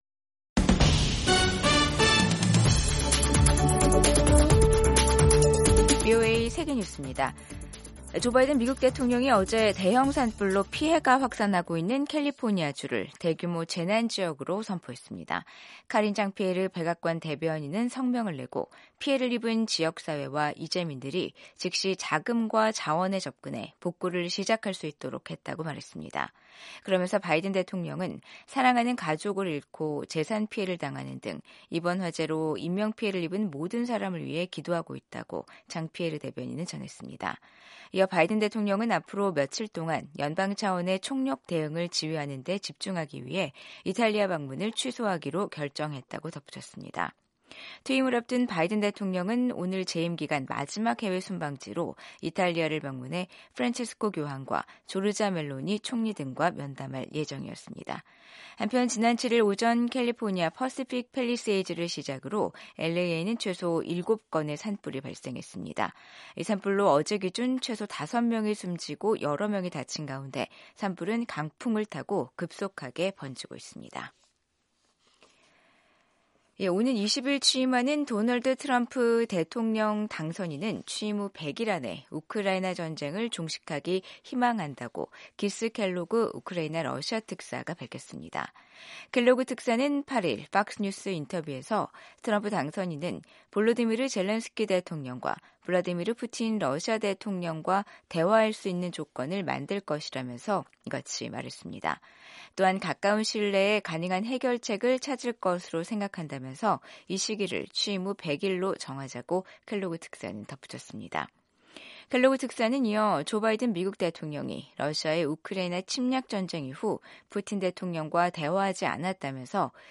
생방송 여기는 워싱턴입니다 2025/1/9 저녁